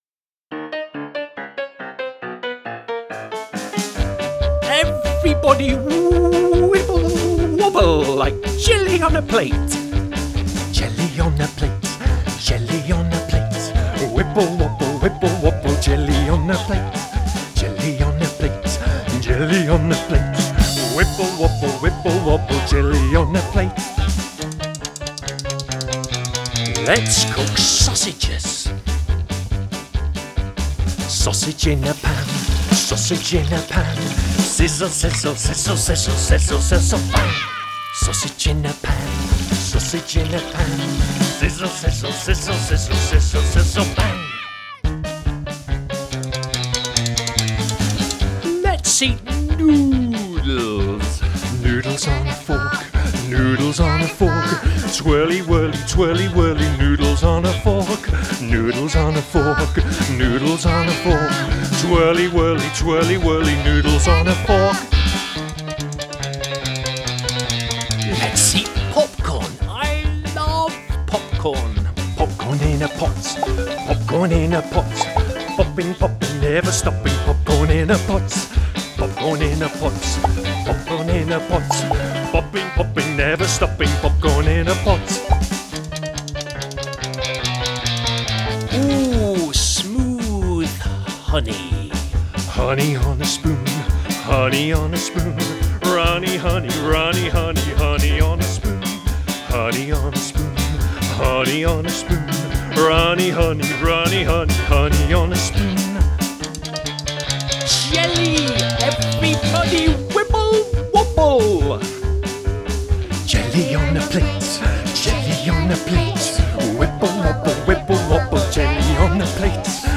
Wibble wobble, wibble wobble — a wiggly song about food
Fun & Punk